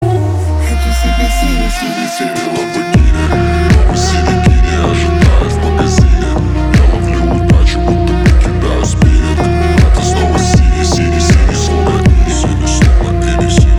• Качество: 320, Stereo
восточные
G-House
дудук